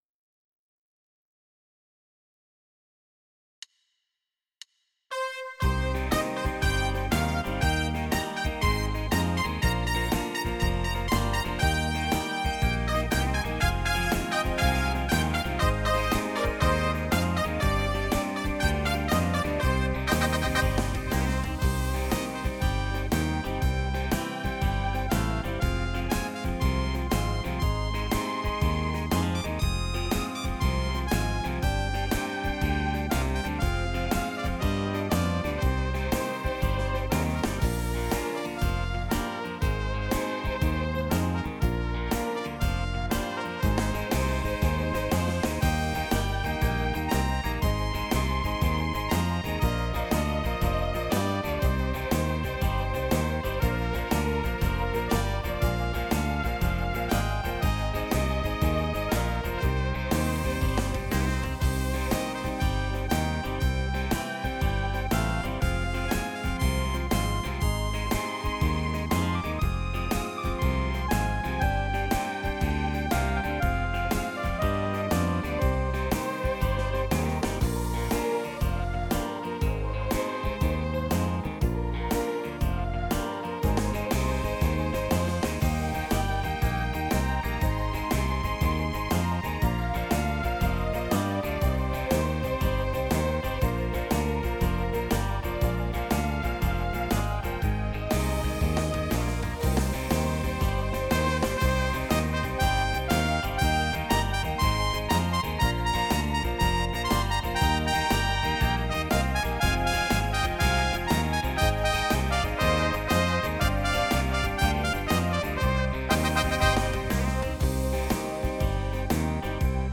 •   Beat  04.